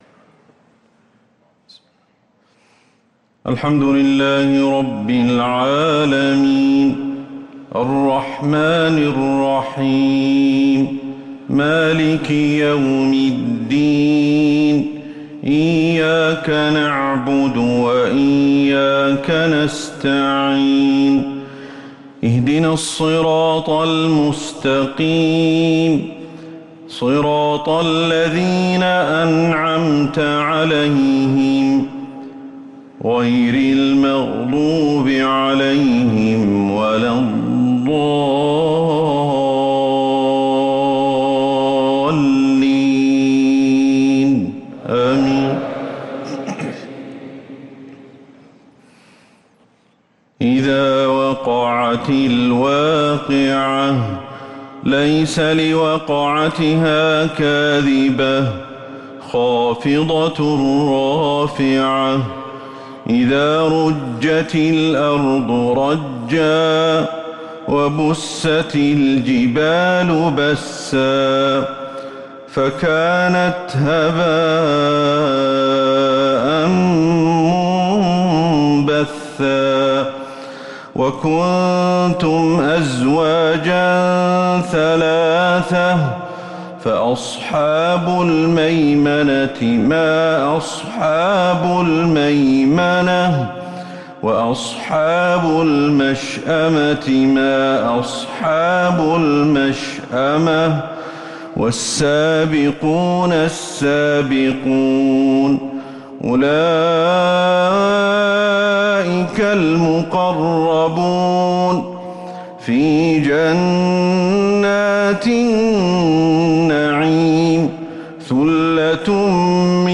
صلاة الفجر للقارئ أحمد الحذيفي 14 ذو الحجة 1444 هـ
تِلَاوَات الْحَرَمَيْن .